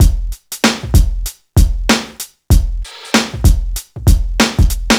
• 96 Bpm Breakbeat Sample F Key.wav
Free breakbeat sample - kick tuned to the F note.
96-bpm-breakbeat-sample-f-key-lre.wav